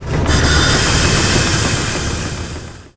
Rumbling Sound
轰隆轰隆